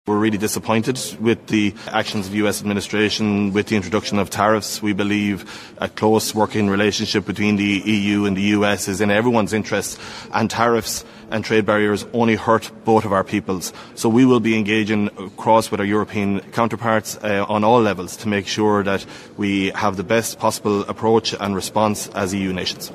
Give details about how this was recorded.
That’s according to the Agriculture Minister who was speaking ahead of an EU meeting in Brussels.